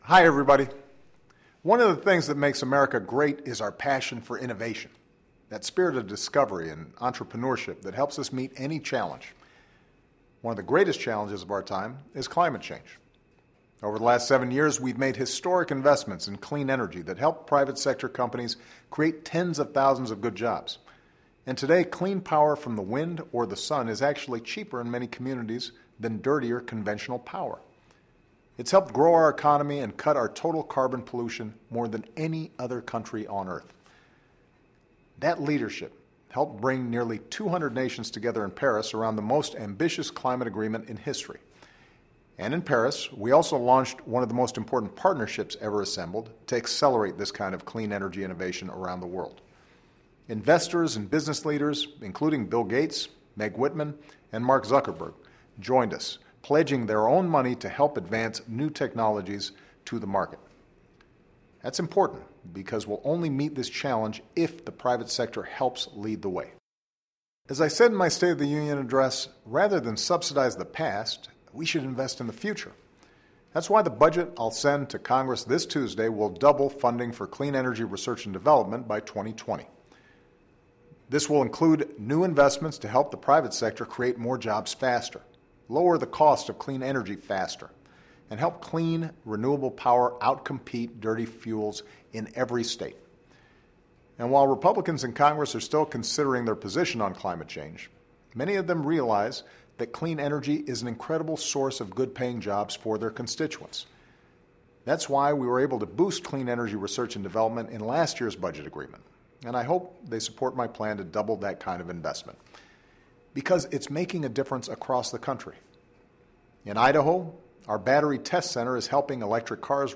Remarks of President Barack Obama as Prepared for Delivery